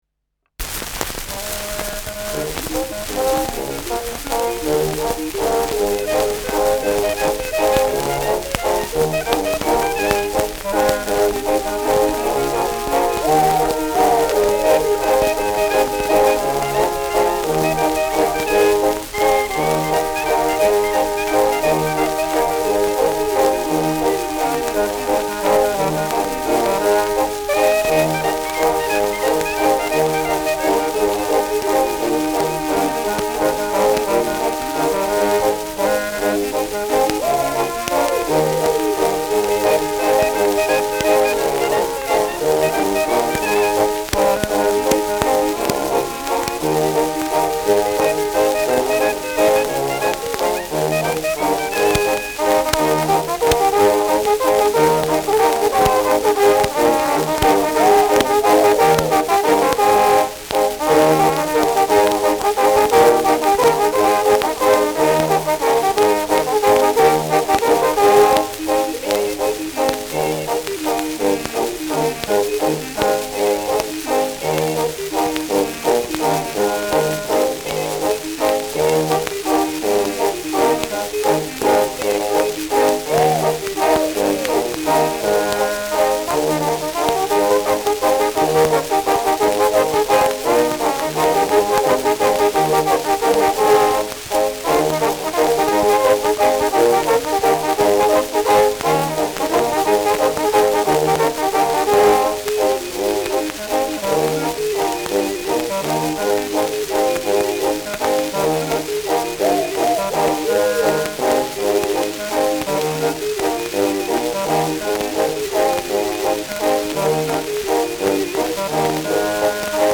Schellackplatte
starkes Rauschen : präsentes Knistern : abgespielt : leiert : gelegentliches Knacken
[unbekanntes Ensemble] (Interpretation)
Mit Juchzern